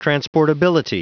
Prononciation du mot transportability en anglais (fichier audio)
Prononciation du mot : transportability